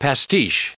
added pronounciation and merriam webster audio
1002_pastiche.ogg